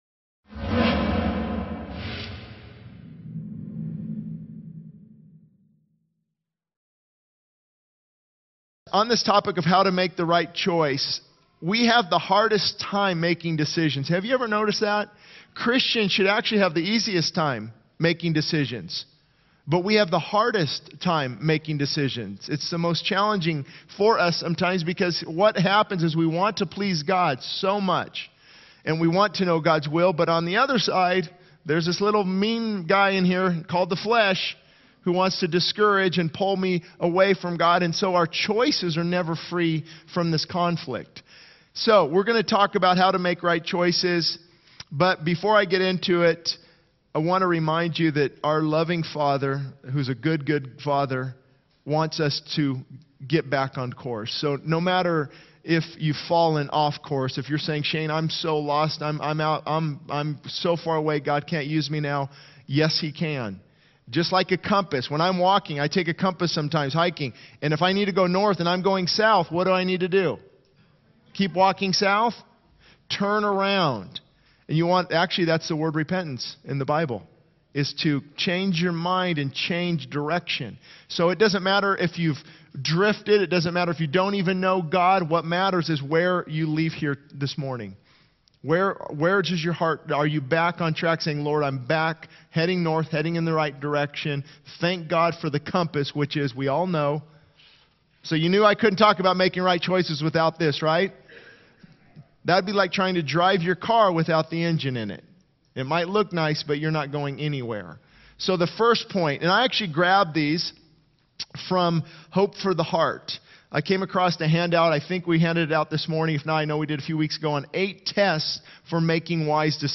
The sermon concludes with a call to surrender to the Holy Spirit for a deeper relationship with God.